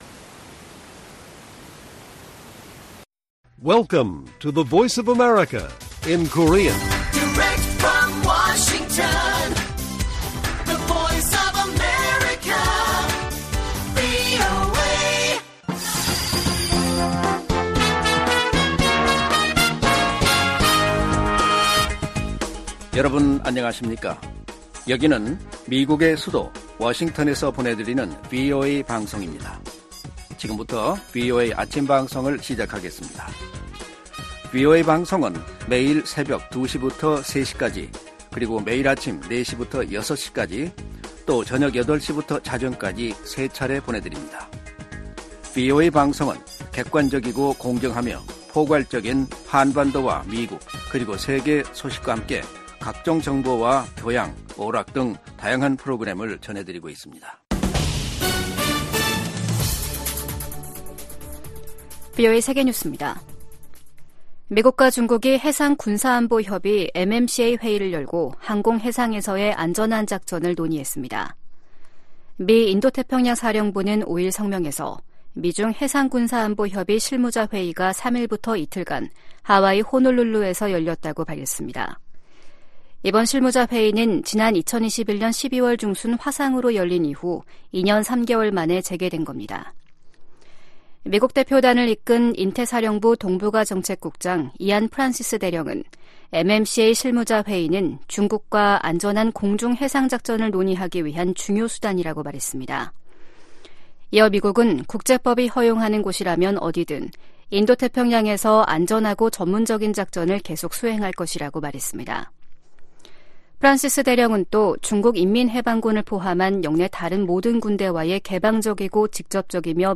세계 뉴스와 함께 미국의 모든 것을 소개하는 '생방송 여기는 워싱턴입니다', 2024년 4월 6일 아침 방송입니다. '지구촌 오늘'에서는 국제사법재판소(ICJ)가 가자지구에 더 많은 인도적 지원을 할 수 있도록 육로 통로를 더 개방하라고 이스라엘 정부에 명령한 소식 전해드리고, '아메리카 나우'에서는 조 바이든 대통령이 바락 오바마, 빌 클린턴 전 대통령과 함께 나선 대선 모금 행사에서 2천500만 달러를 모은 이야기 살펴보겠습니다.